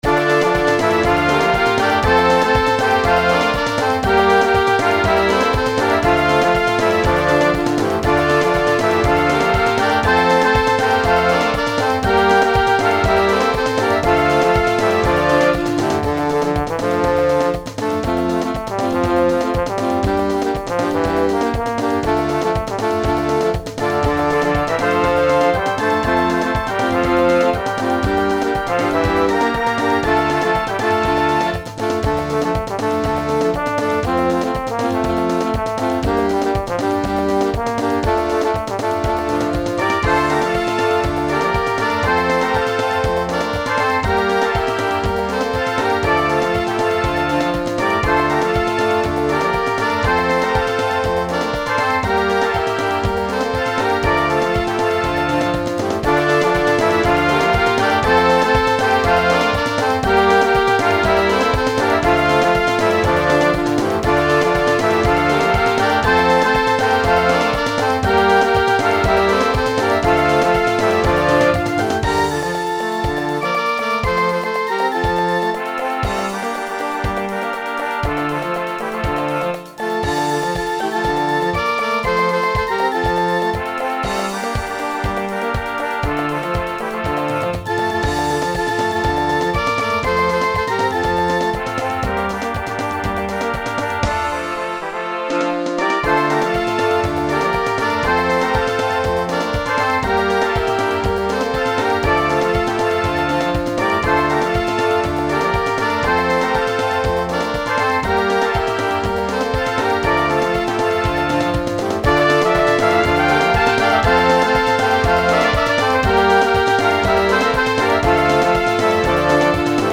Catégorie : Arrangement musical
Type de formation : Fanfare / Harmonie / Banda
Pré-écoute non téléchargeable · qualité réduite